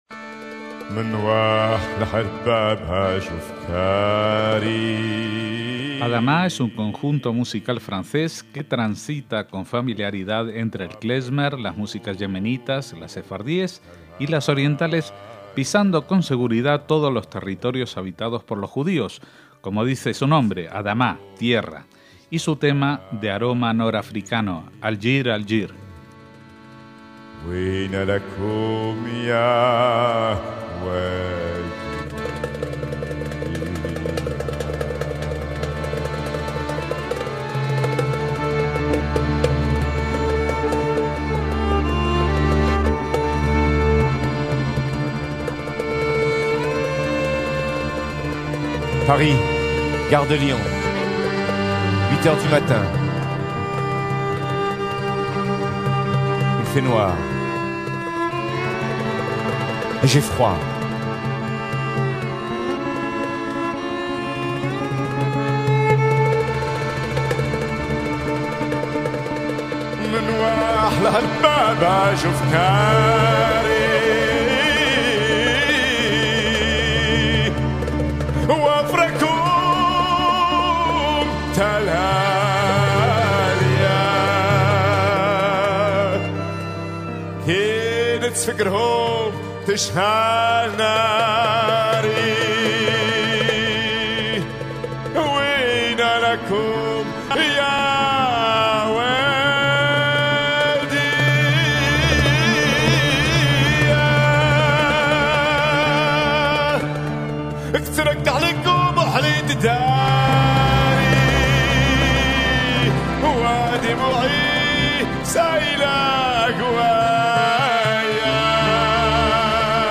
folklore puro y conmovedor
Sus canciones son nostálgicas o alegres